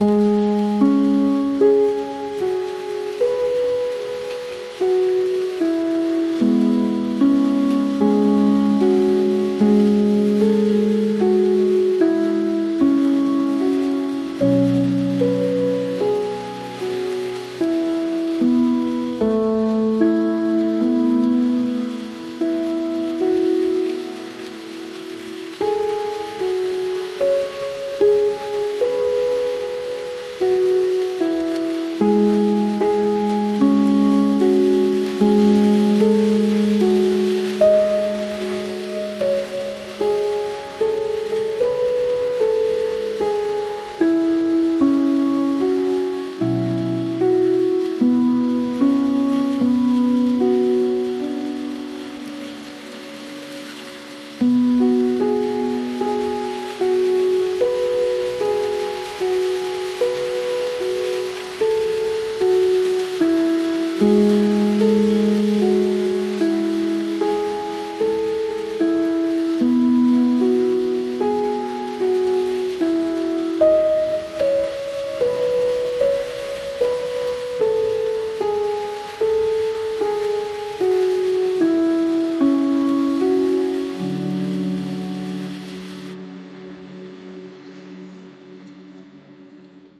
公園の小道には誰もいないが、雨音と時折聞こえる鳥の声が、静かで落ち着いた雰囲気を作り出している。
calm-meditation_0420_2.mp3